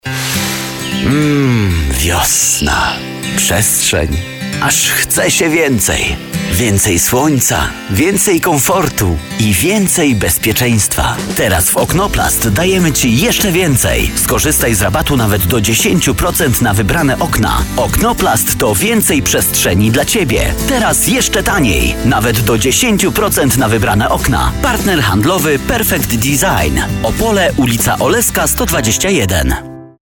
Male 30-50 lat
Spot reklamowy